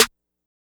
{Snare} Main Piece.wav